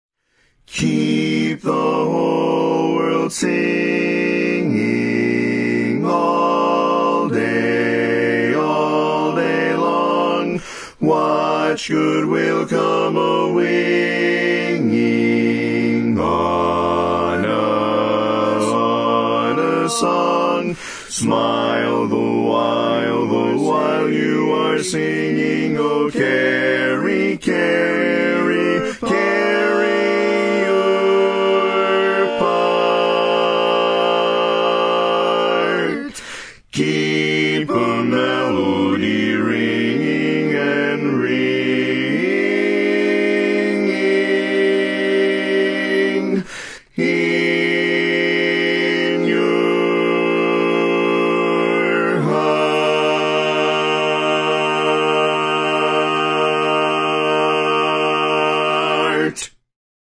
Bass Part
Keep the Whole World Singing - Bass.mp3